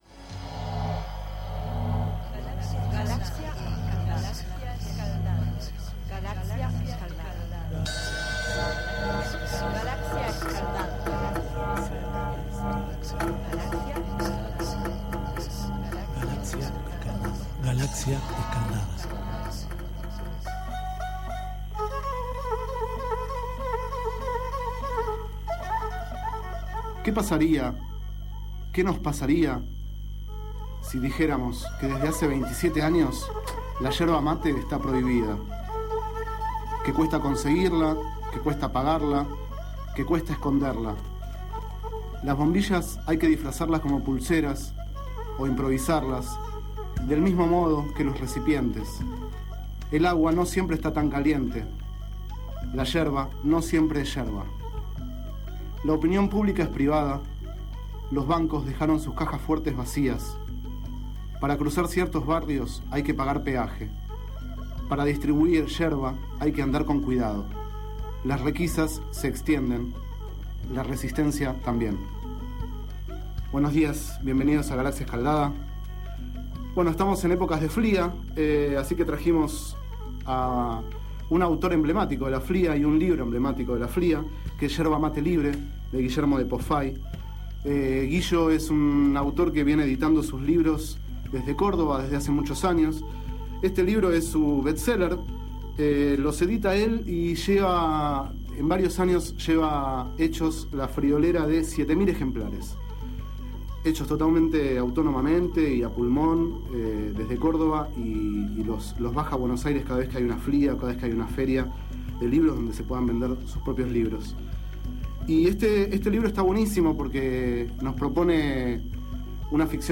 Este es el 12º micro radial, emitido en el programa Enredados, de la Red de Cultura de Boedo, por FMBoedo, realizado el 28 de mayo de 2011, sobre el libro Yerba mate libre, de Guillermo de Pósfay.